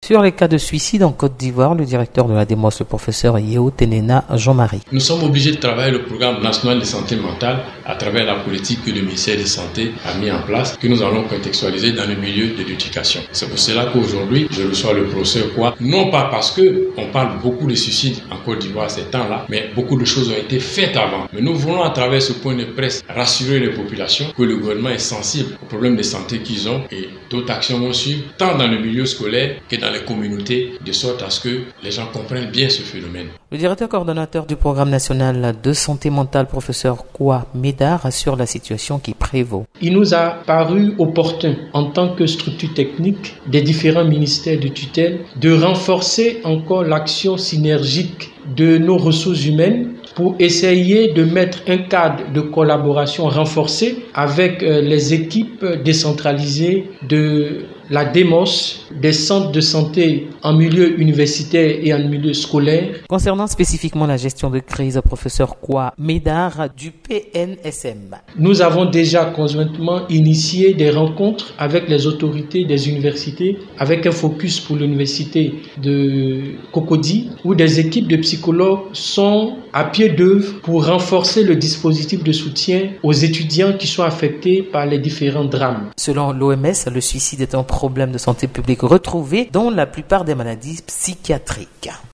Point de presse sur la situation actuelle de suicide en milieu scolaire et universitaire - Site Officiel de Radio de la Paix
Autour de ce thème s’est tenu Hier Lundi 23 Janvier un point presse à la salle de conférence de la Direction de la mutualité et des œuvres sociales en milieu scolaire (D.M.O.S.S) à Abidjan.